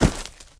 sounds / material / human / step / default3.ogg